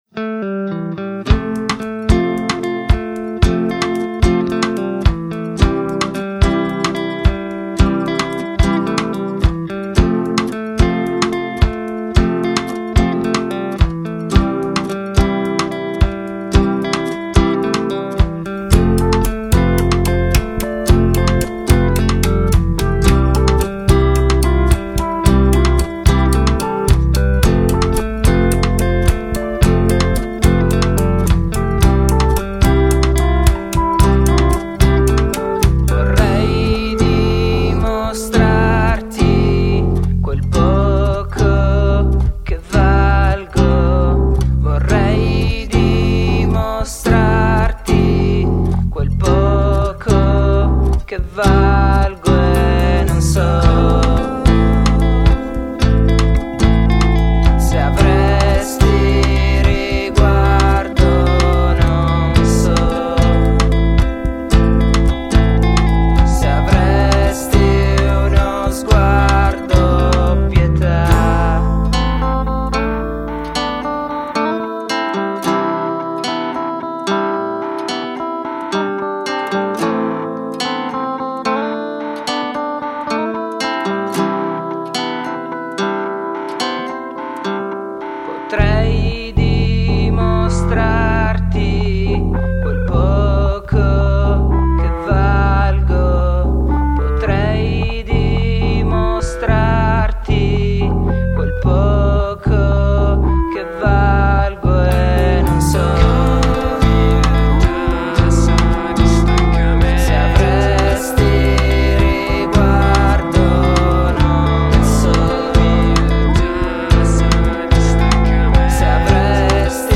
MUSIC>alternative rock